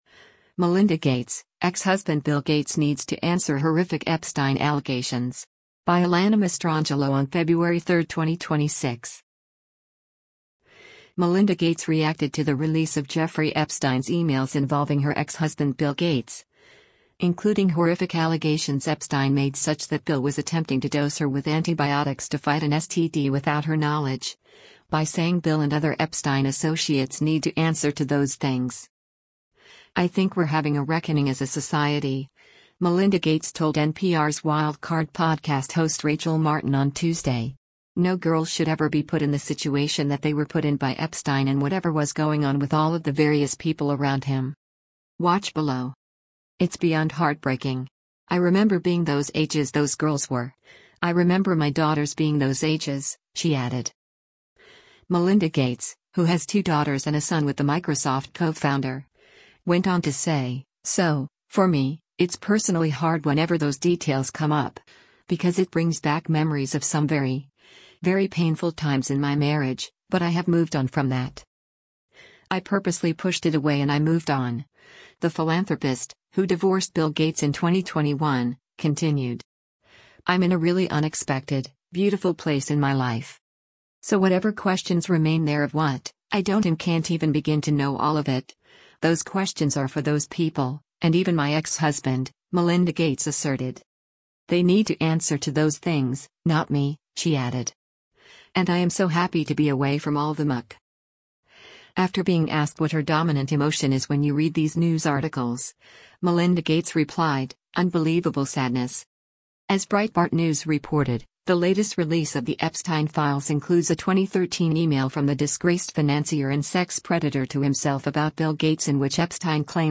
“I think we’re having a reckoning as a society,” Melinda Gates told NPR’s Wild Card podcast host Rachel Martin on Tuesday.